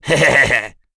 Bernheim-Vox_Happy2_z.wav